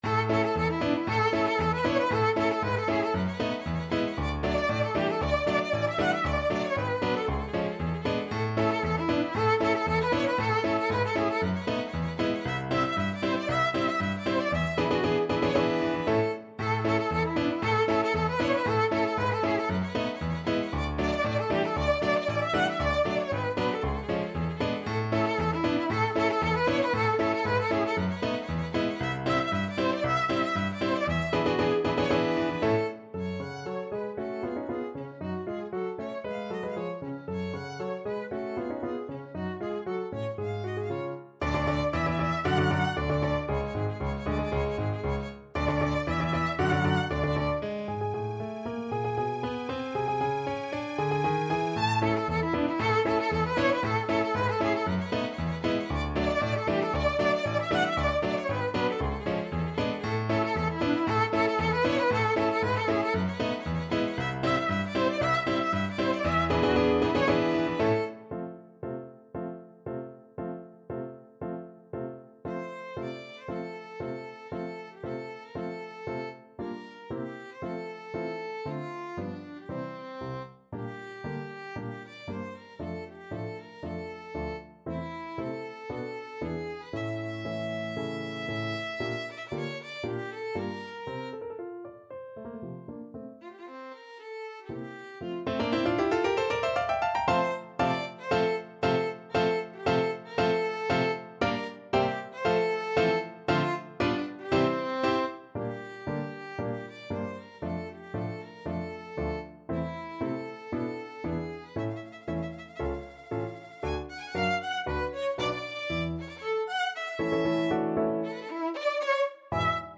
Classical Bizet, Georges Carmen Overture (Prelude) Violin version
2/4 (View more 2/4 Music)
A major (Sounding Pitch) (View more A major Music for Violin )
Allegro giocoso =116 (View more music marked Allegro giocoso)
Classical (View more Classical Violin Music)